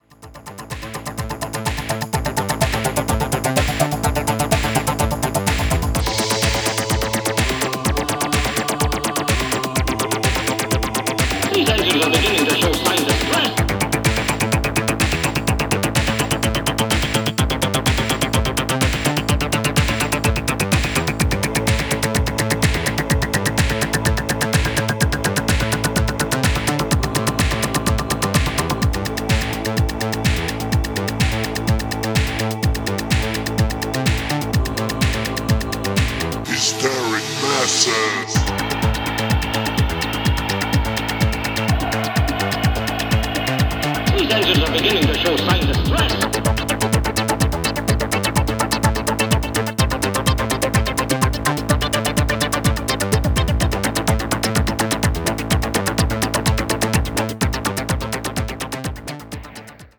本作も御多分に洩れず80年代から地続きなゴリゴリのハードビートが鳴ってます。